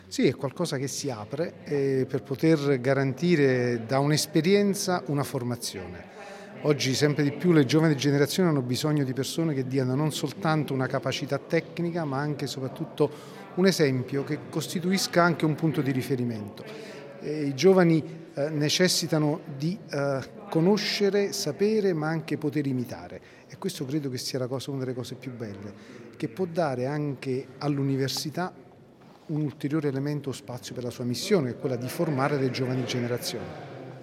Nell’Aula Magna della Pontificia Università Lateranense si è svolta questa mattina la cerimonia del conferimento del titolo di “Magister in cooperazione internazionale” all’ambasciatore dell’Italia presso la Santa Sede Pietro Sebastiani, che è stato direttore generale della Cooperazione al Ministero degli Esteri italiano.